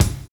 VINYL 6 BD.wav